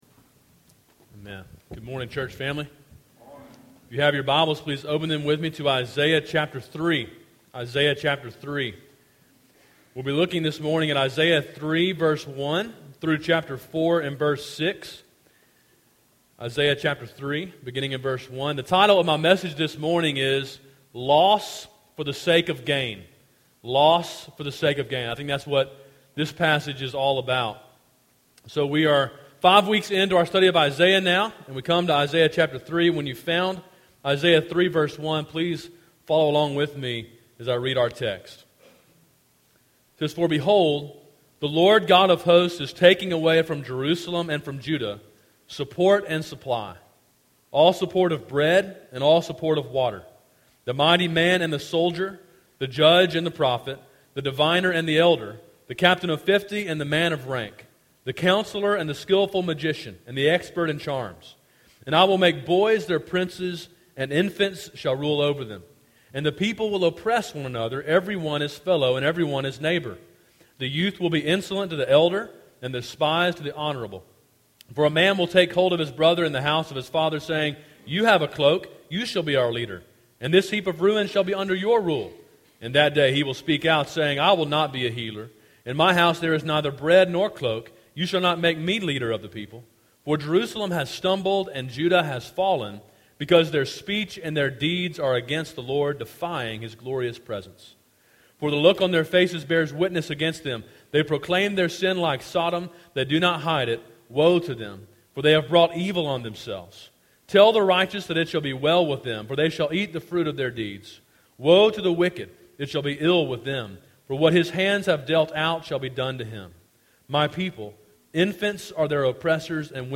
Sermon in a series on the book of Isaiah.